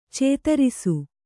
♪ cētarisu